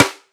SNARE_DONT_FUCK_WIT_ME.wav